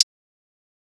Hi-Hat (Impossible).wav